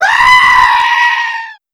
goat.wav